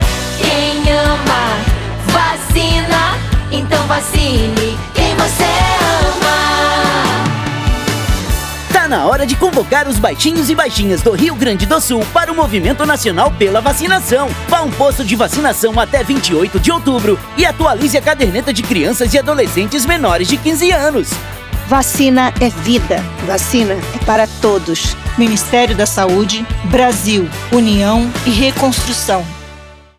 Áudio - Spot 30seg - Campanha de Multivacinação no Rio Grande do Sul - 1,1mb .mp3